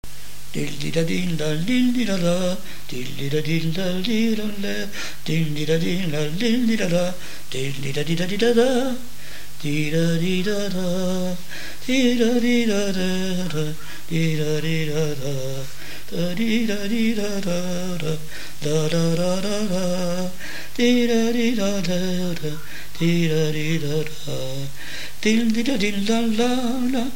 Mémoires et Patrimoines vivants - RaddO est une base de données d'archives iconographiques et sonores.
air de marche de noces
Pièce musicale inédite